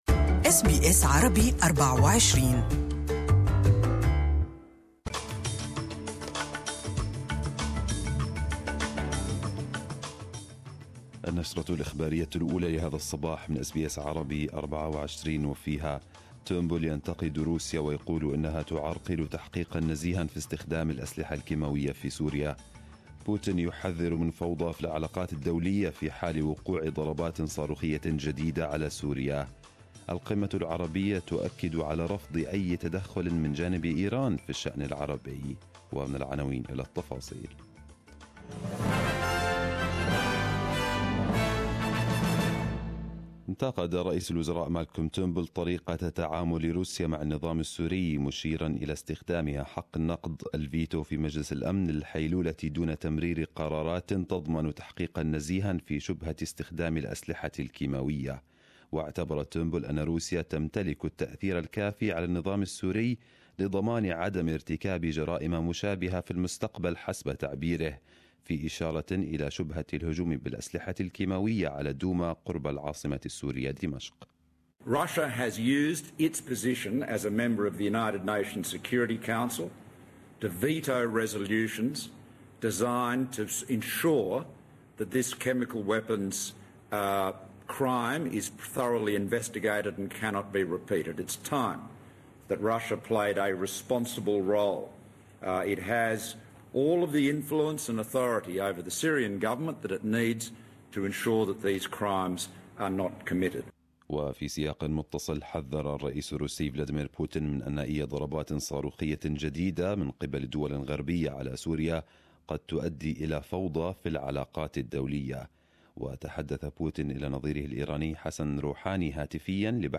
Arabic News Bulletin 16/04/2018